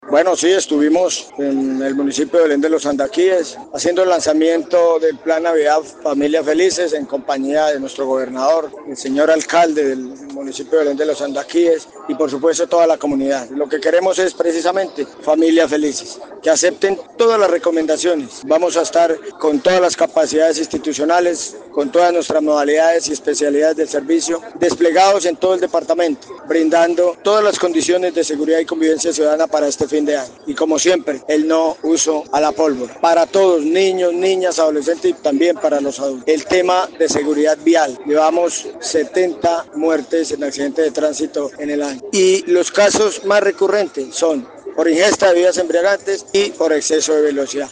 CORONEL_JULIO_GUERRERO_PLAN_NAVIDAD_-_copia.mp3